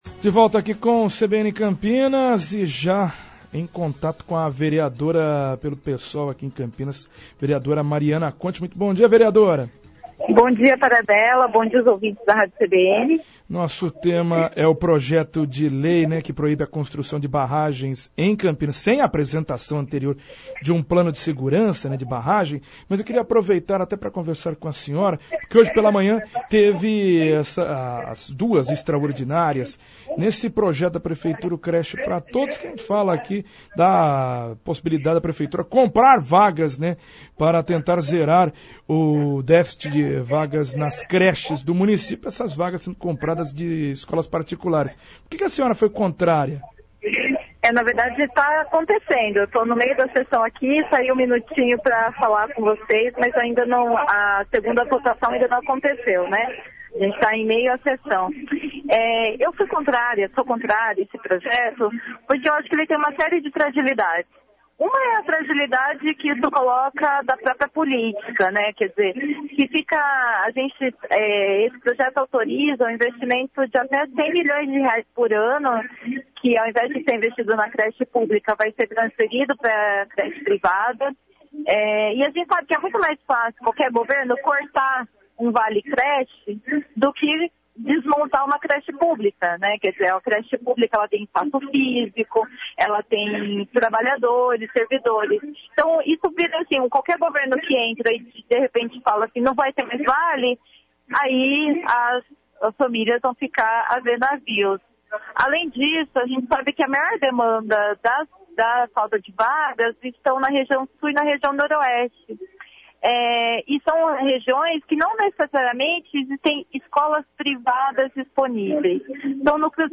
Vereadora do PSOL de Campinas, Mariana Conti fala sobre seu pedido de urgência para votarem um projeto contra as barragens em Campinas